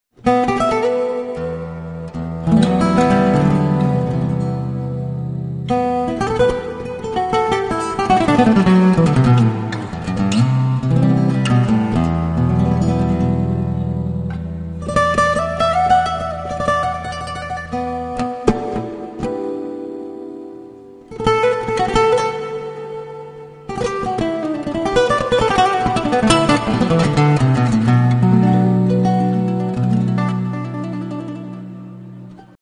traditional Tajik music